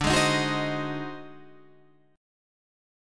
D 7th Flat 9th Flat 5th